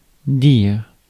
Ääntäminen
US : IPA : [ˈseɪ.ɪŋ]